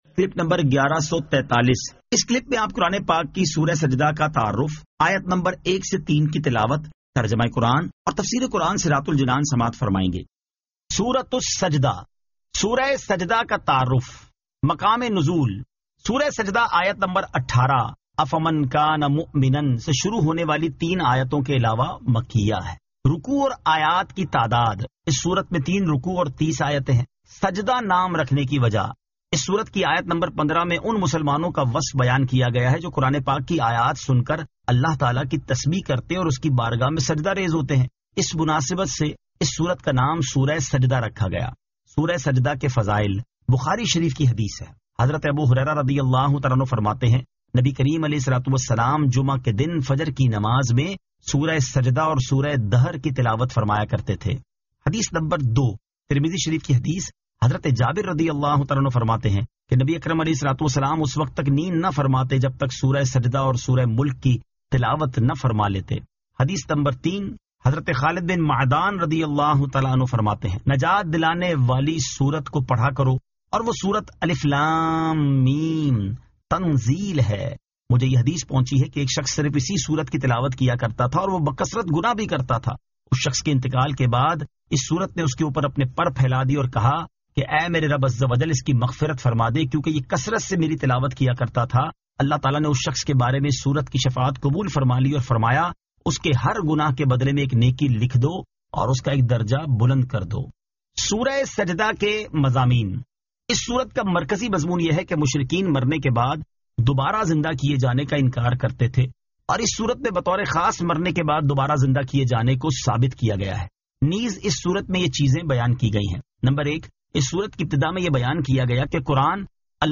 Surah As-Sajda 01 To 03 Tilawat , Tarjama , Tafseer